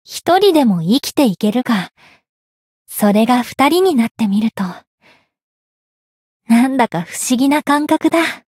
灵魂潮汐-密丝特-情人节（摸头语音）.ogg